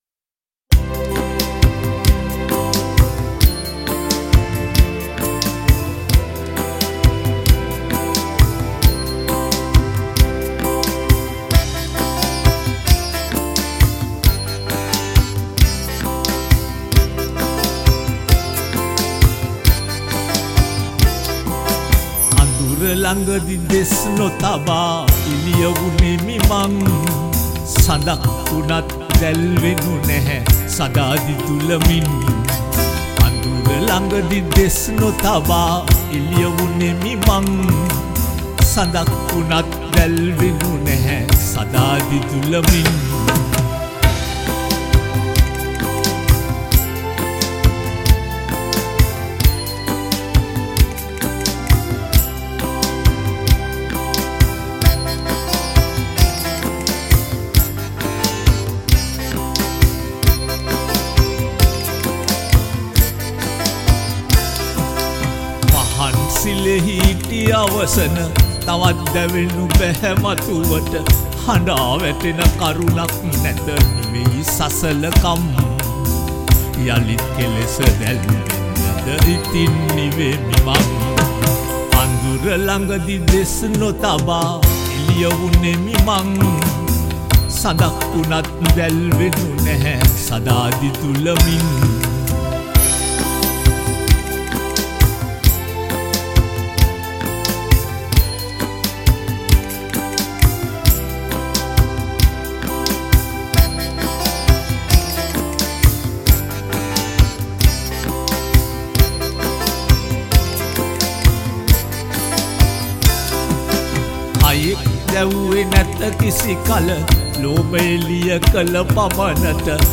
All these songs were recorded (or remastered) in Australia.
Vocals